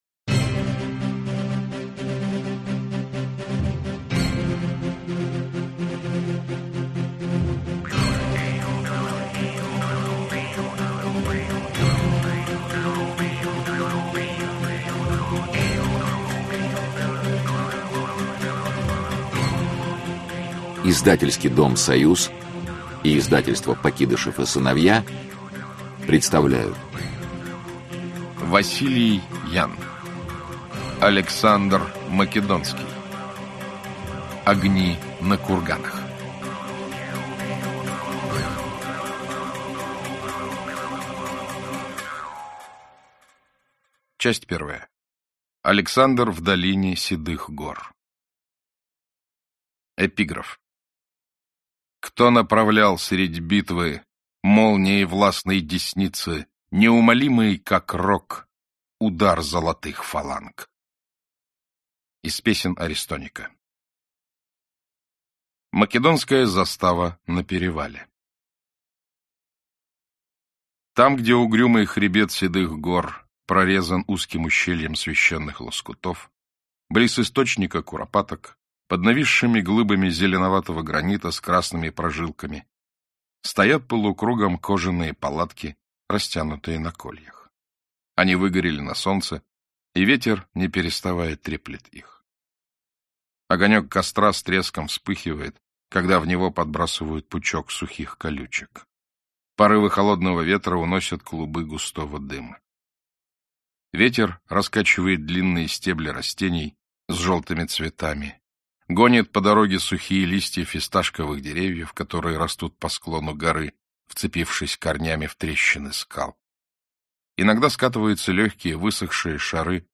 Аудиокнига Александр Македонский. Огни на курганах | Библиотека аудиокниг